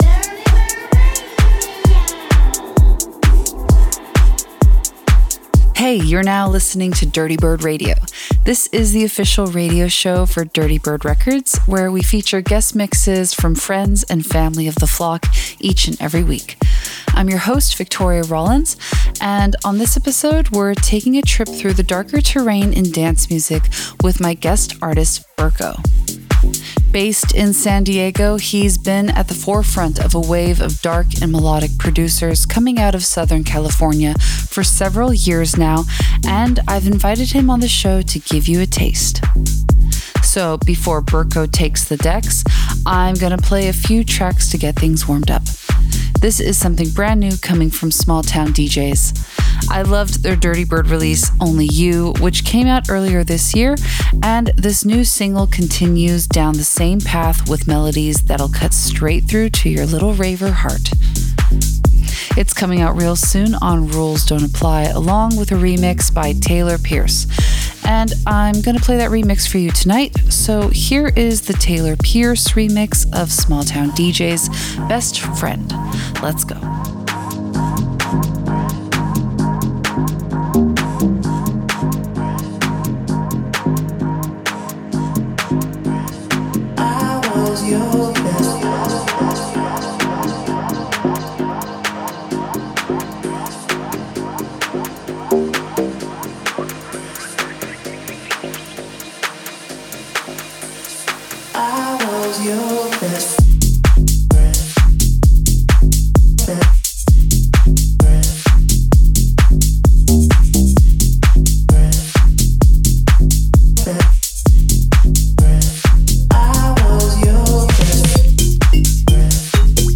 VR warm-up set
guest set